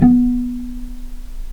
vc_pz-B3-pp.AIF